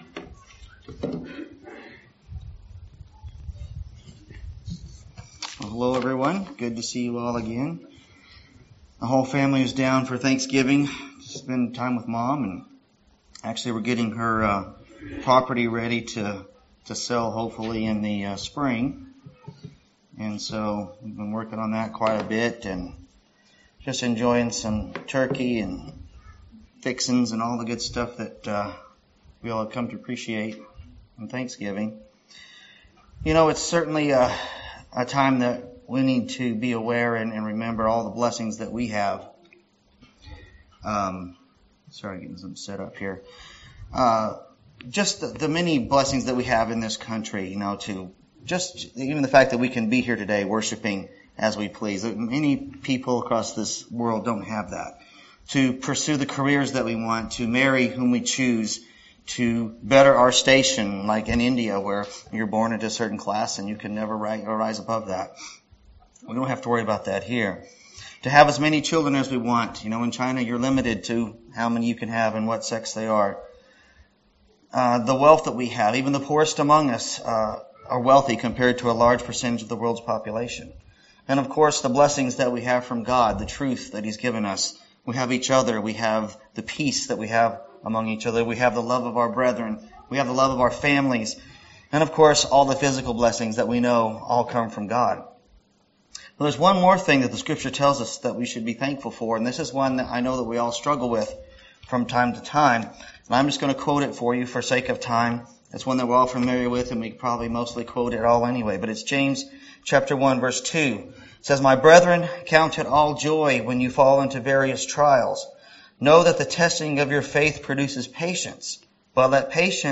Sermons
Given in Lubbock, TX